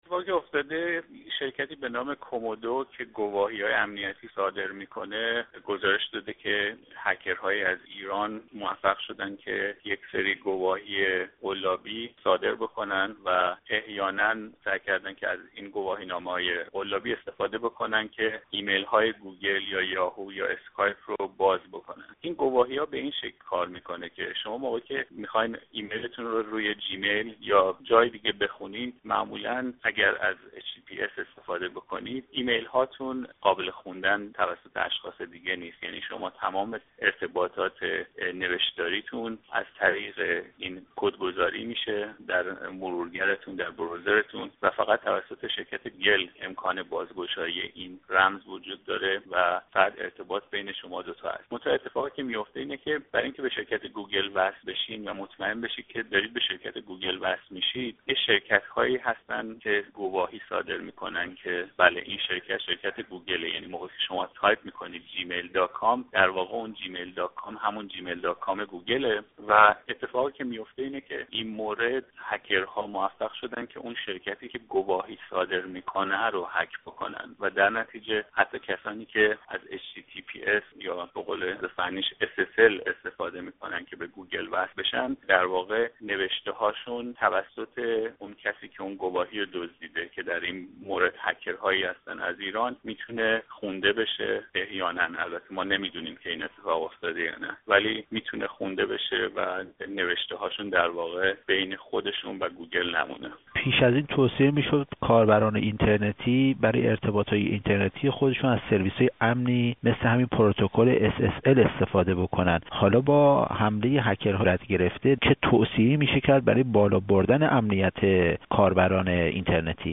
گفت وگو